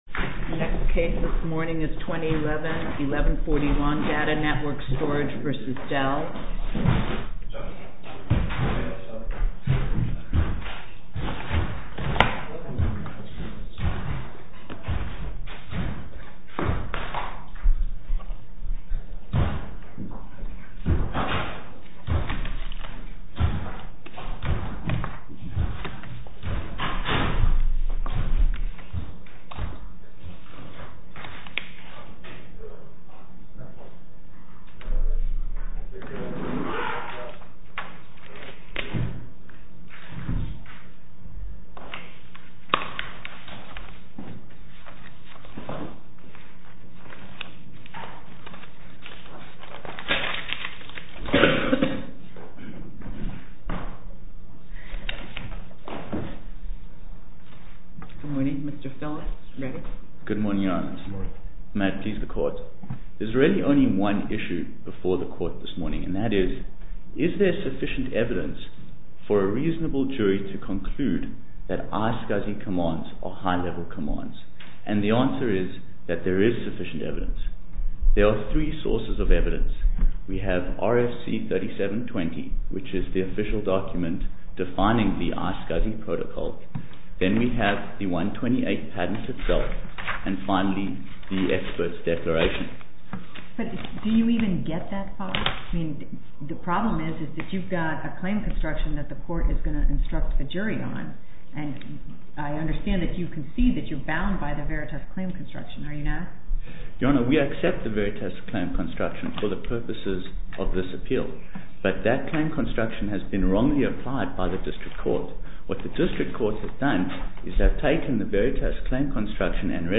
Oral argument audio posted: DATA NETWORK STORAGE V. DELL (mp3) Appeal Number: 2011-1141 To listen to more oral argument recordings, follow this link: Listen To Oral Arguments.